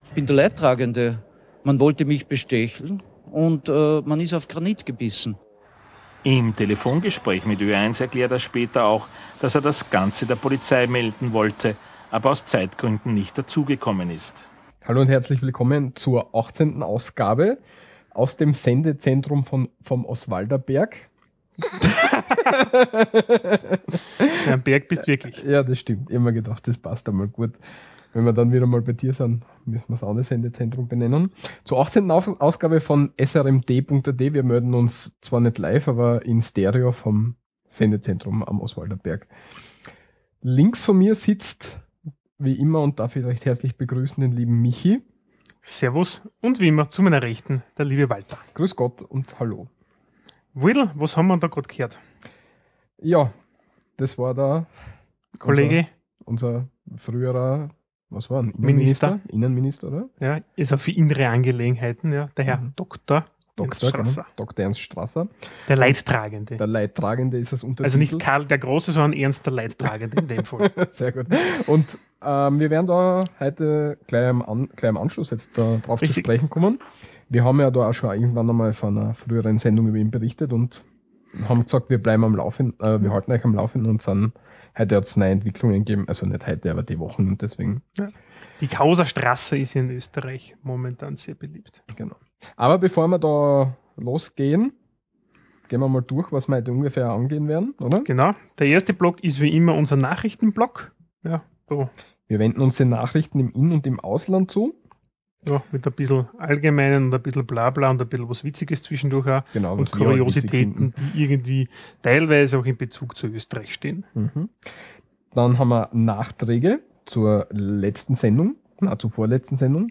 In Stereo und direkt aus dem Sendestudio am Oswalderberg! In unserer neuen, glorreichen Kategorie Leben in Österreich geht es diesmal um die Sponsion. Sprachlich wenden wir uns den Eigenheiten in der Buchstabiertafel zu und erklären, warum es in Österreich anders um die Zwei bestellt ist.